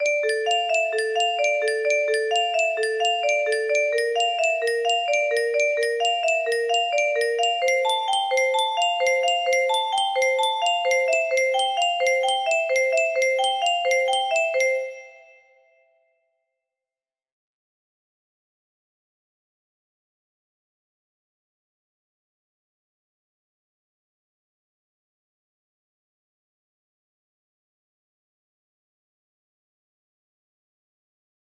BPM 65